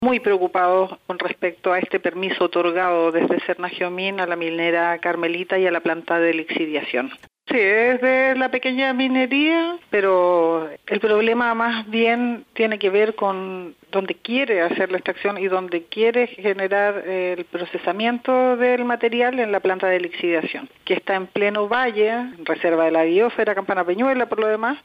En conversación con la Radio, Paula Castro, concejala independiente apoyada por el Frente Amplio, comentó que si bien este proyecto está categorizado como “pequeña minería”, el problema radica en el lugar donde se quiere generar la extracción.